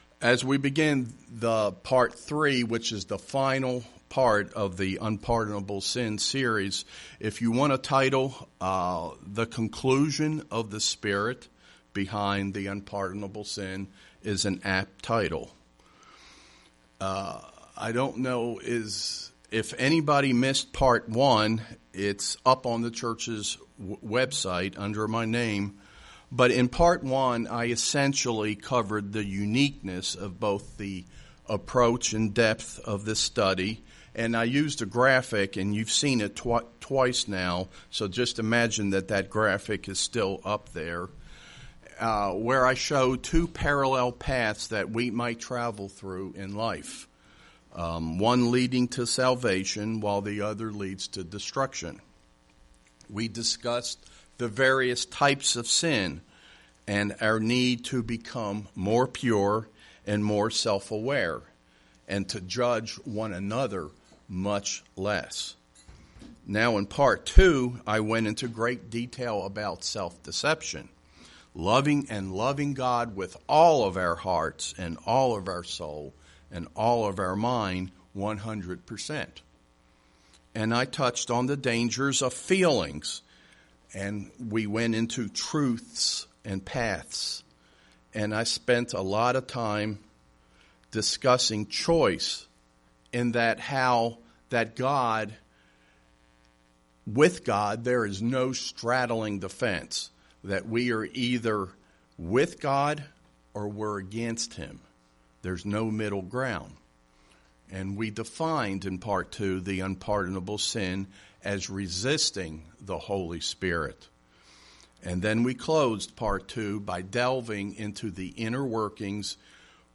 Here in part 3 we conclude the sermon series on the unpardonable sin and tie it in with parts 1 and 2. We also delve into all that we must understand in order to ensure we head onto the path that leads to salvation and not to destruction.